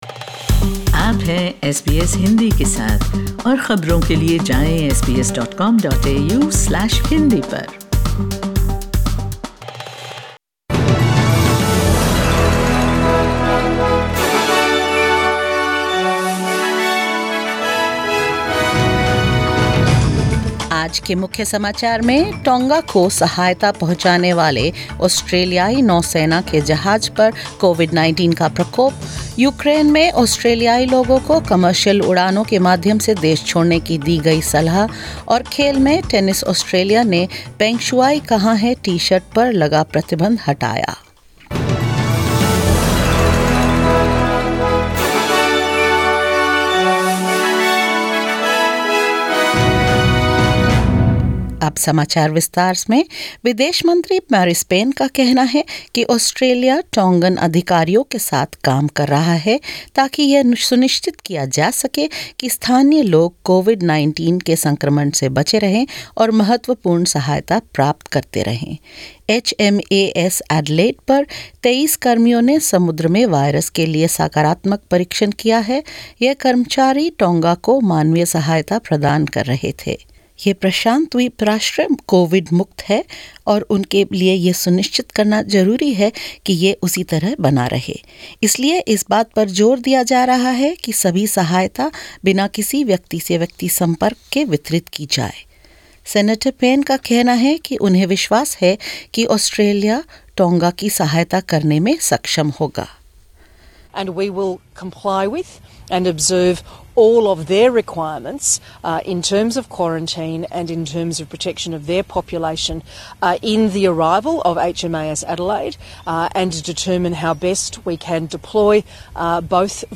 In this latest SBS Hindi bulletin: Australian Navy vessel HMAS Adelaide to deliver aid to COVID-free Tonga despite coronavirus outbreak on board; 1,400 Australians in Ukraine urged to leave the country amid fears of a potential Russian invasion; Tennis Australia reverses its ban on 'Where is Peng Shuai?' -t-shirts and banners at the Australian Open and more.